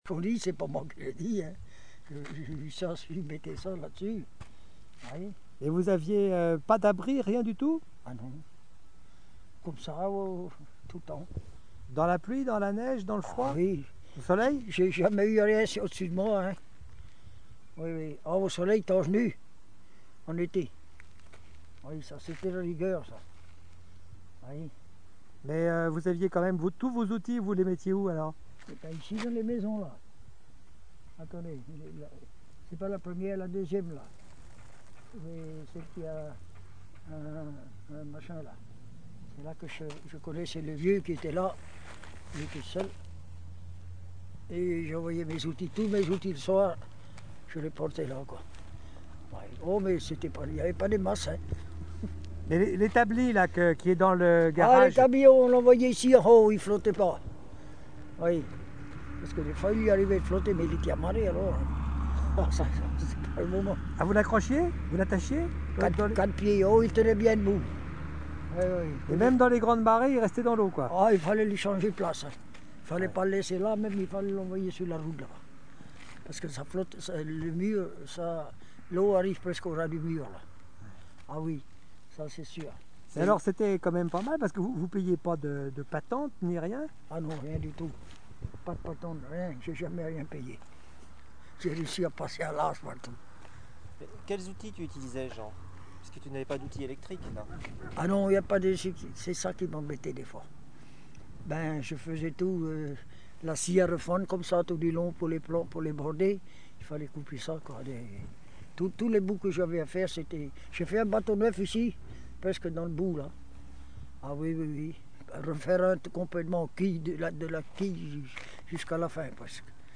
Témoignage oral